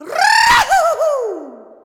RRRRRAUHUU.wav